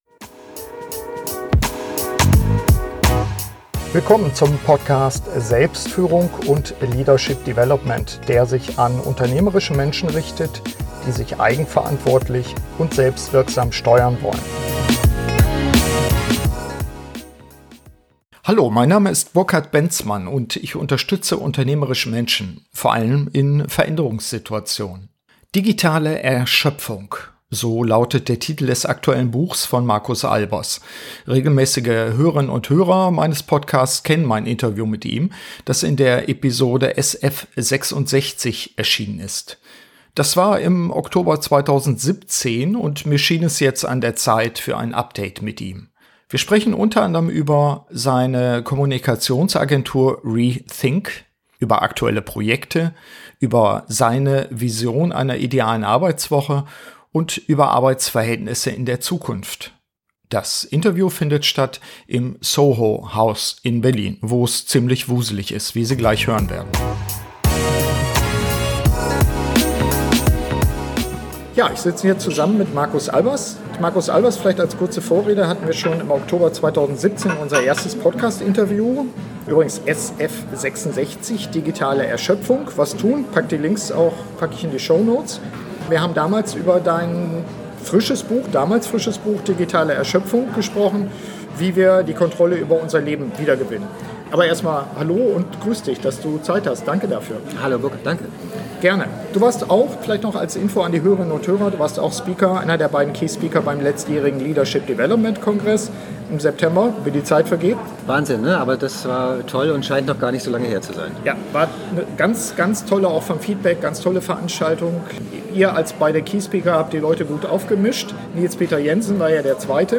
Update-Interview